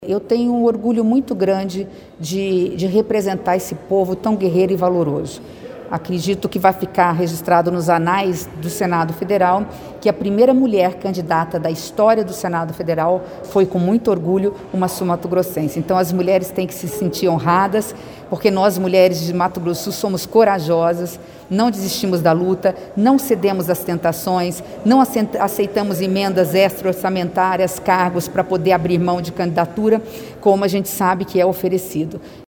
É possível, corta gordura, mas o brasileiro não pode passar fome”, disse em entrevista à Rádio CBN de Campo Grande.